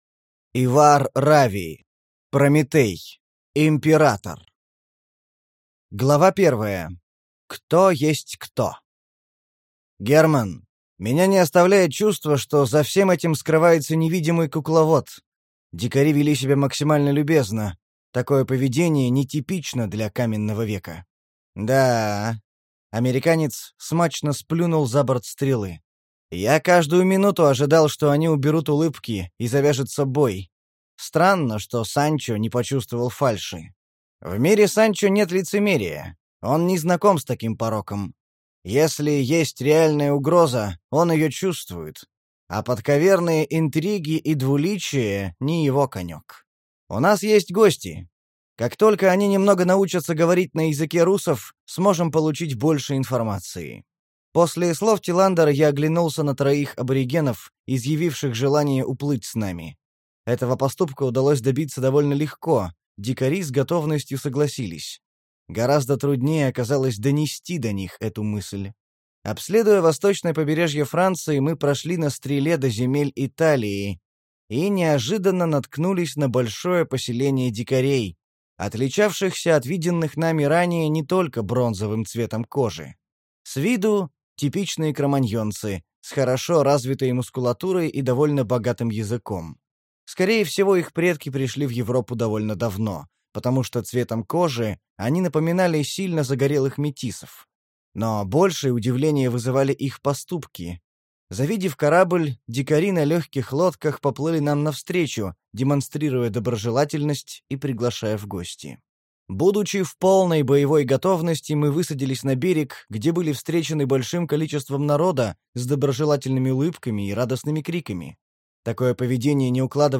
Аудиокнига Прометей: Император | Библиотека аудиокниг